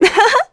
Juno-Vox_Happy2_kr.wav